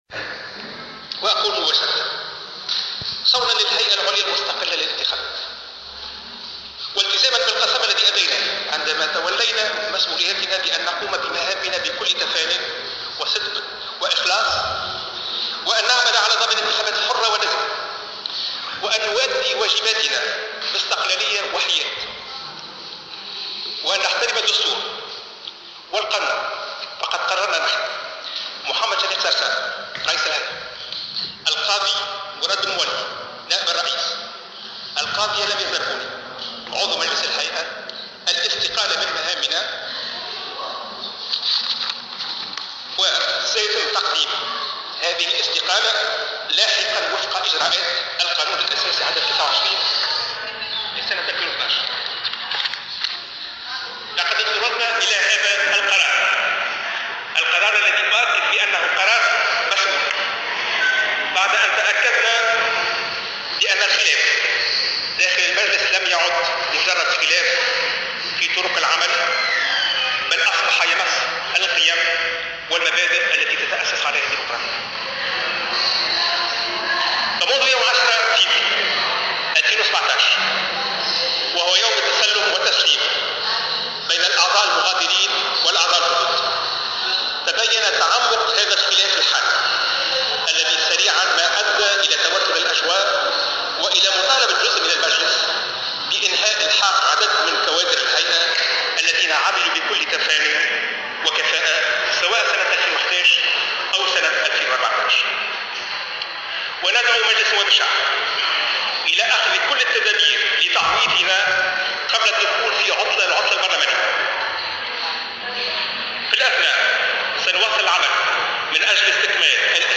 Chafik Sarsar a évoqué, lors d'une conférence de presse tenue mardi, les raisons qui l'ont poussé à démissionner de l'Instance Supérieure Indépendante pour les Élections (ISIE).